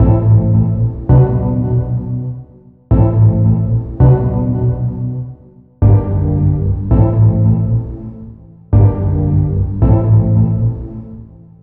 01 Old Bristolians 165 Amin.wav